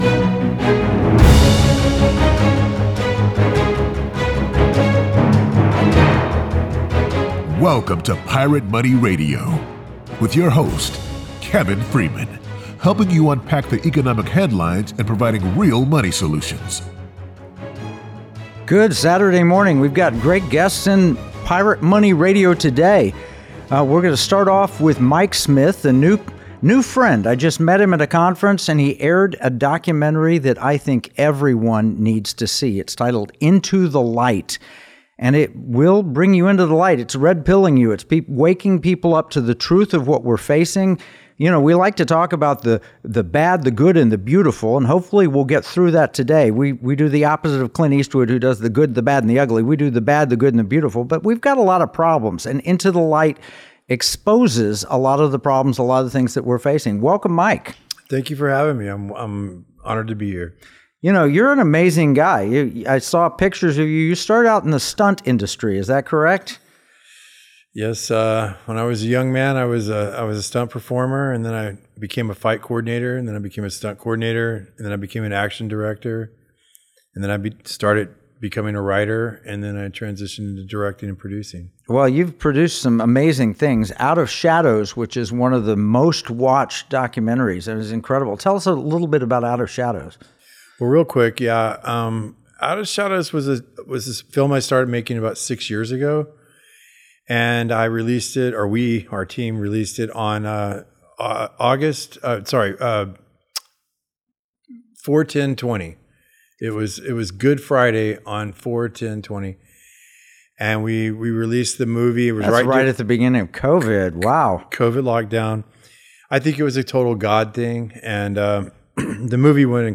Investigative journalist Lara Logan and Lieutenant General Michael Flynn share their experiences, exposing the systematic efforts to control public perception. Don’t miss this eye-opening revelation on how powerful forces shape our realities, and learn how to see through the shadows.